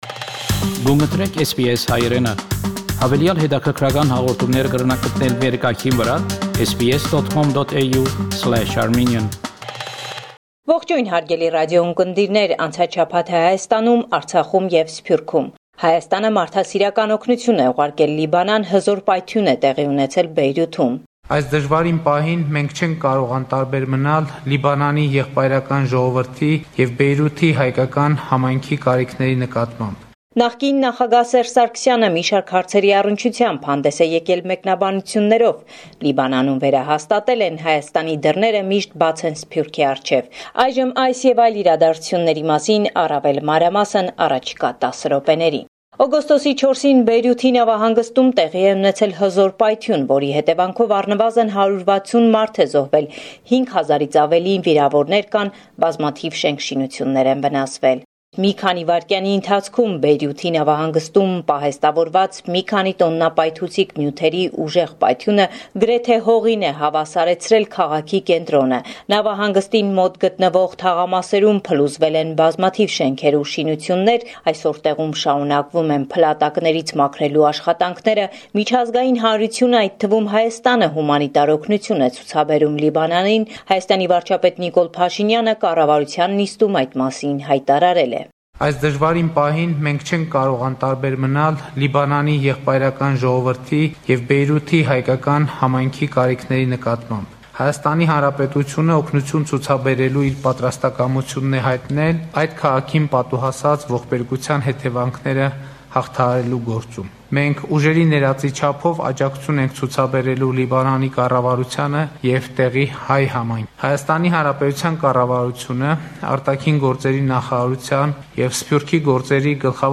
Latest News from Armenia – 11 August 2020